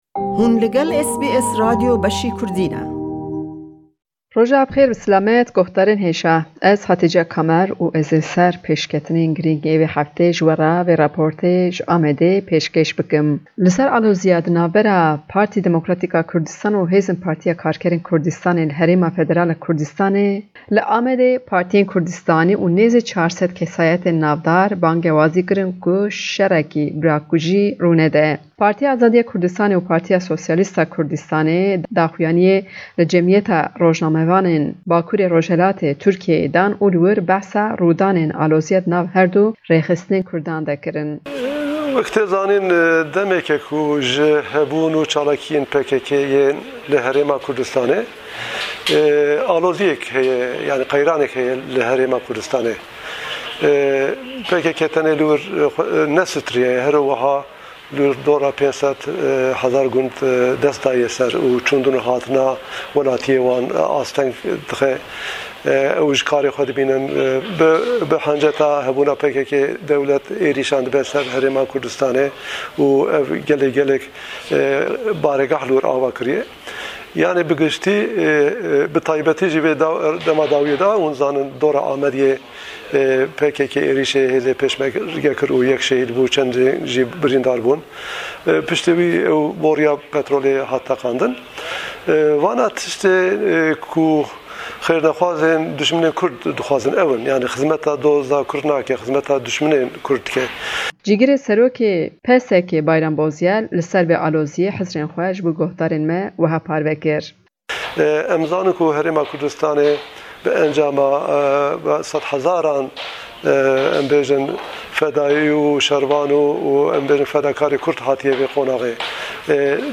di raporta vê hefteyê ji Amedê: daxuyaniya partiyên Kurdistanî li ser aloziyên navbera PKK û PDKê.